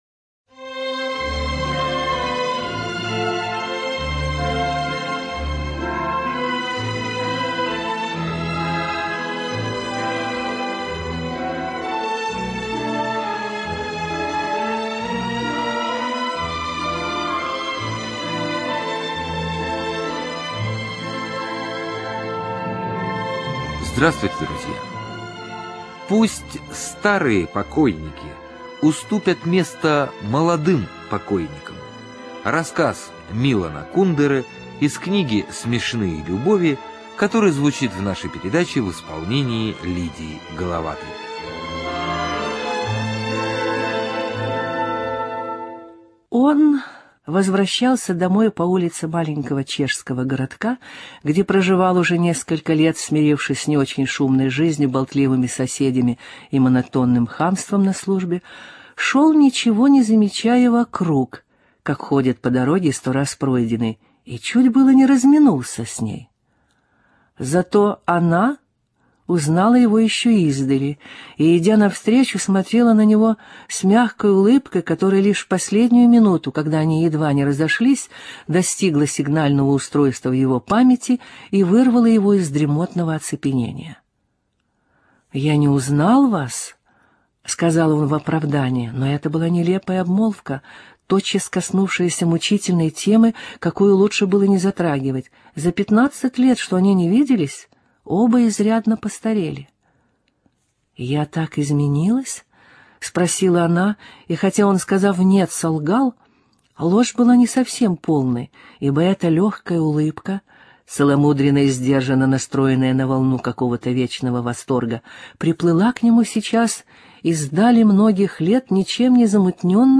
ЖанрСовременная проза